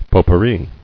[pot·pour·ri]